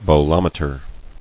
[bo·lom·e·ter]